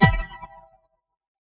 lightimpact2.wav